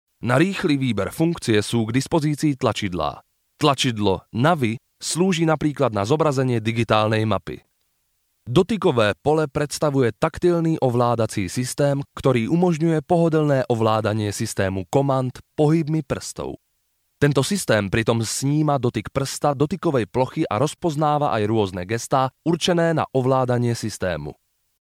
Slovak voice over.
Slovak  female voice overs   locutores eslovacos, slovak voice over Slovak  male voice overs